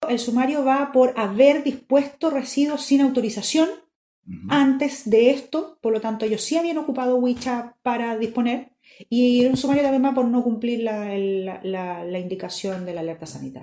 Así lo dijo Scarleth Molt, seremi de Salud.